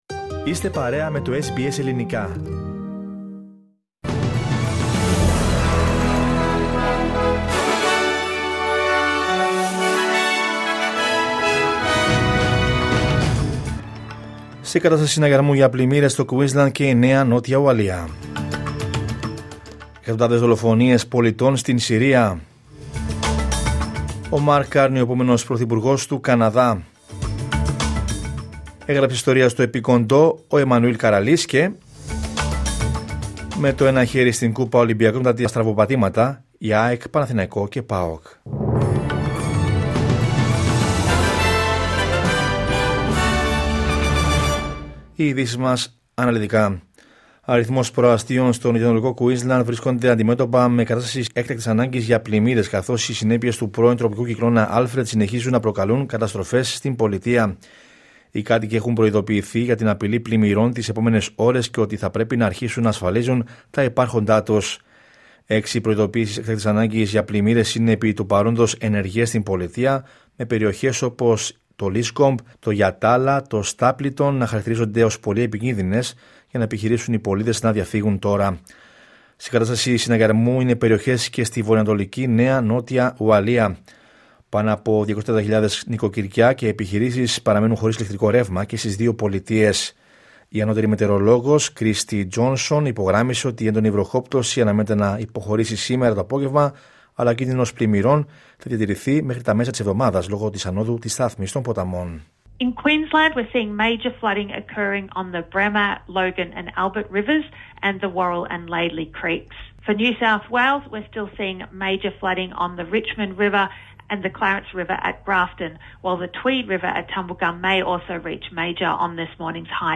Δελτίο Ειδήσεων Δευτέρα 10 Μαρτίου 2025